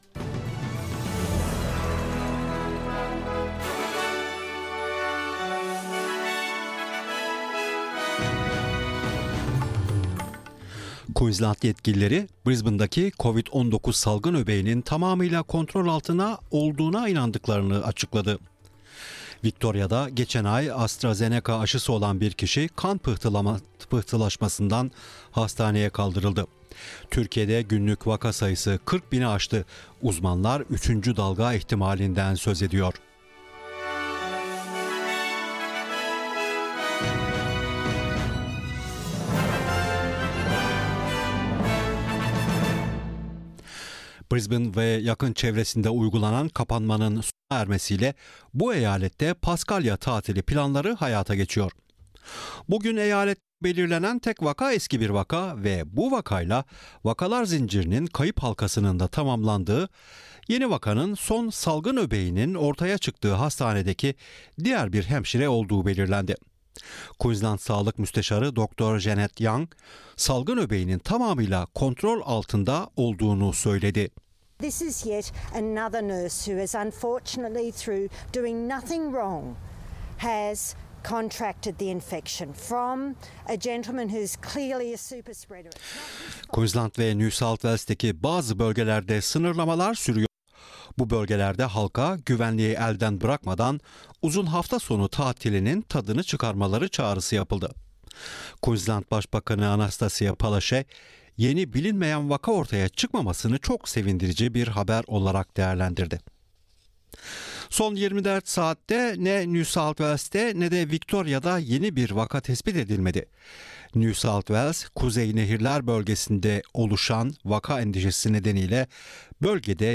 SBS Türkçe Haberler 2 Nisan